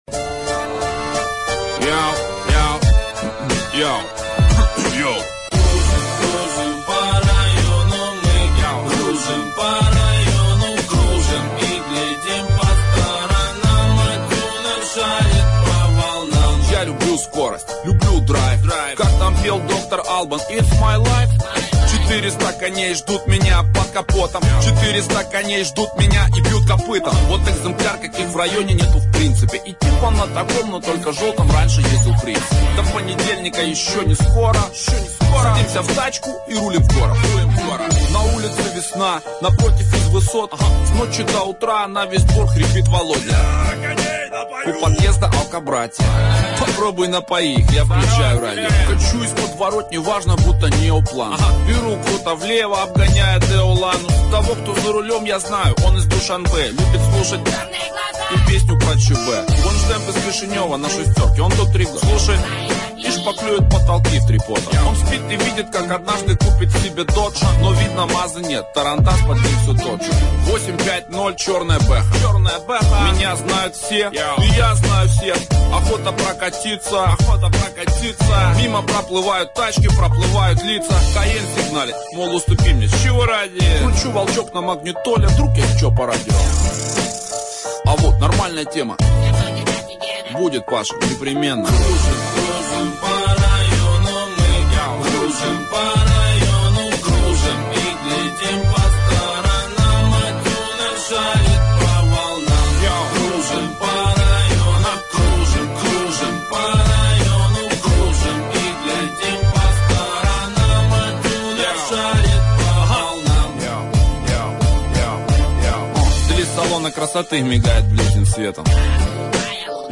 Список файлов рубрики R*a*P